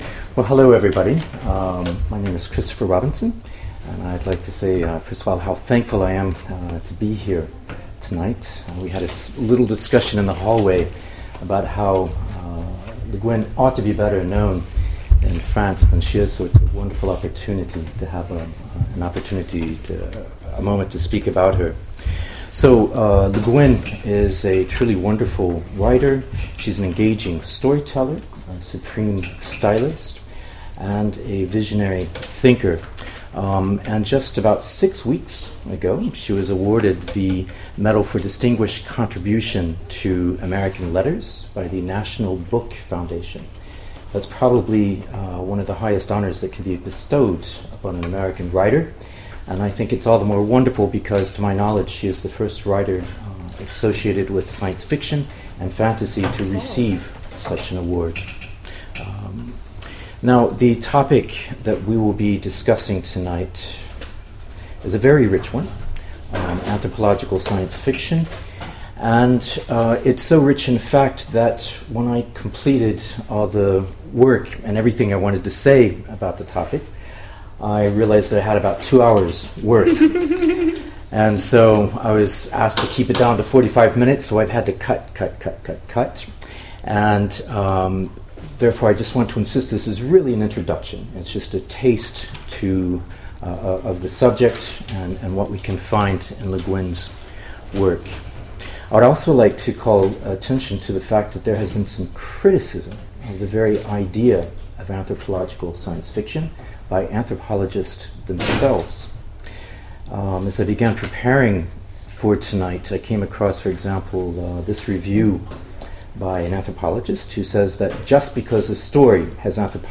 Utopiales 2014 : Cours du soir - L'observateur alien : introduction à la science-fiction anthropologique d’Ursula K. Le Guin
Mots-clés Extraterrestre Conférence Partager cet article